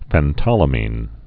(fĕn-tŏlə-mēn)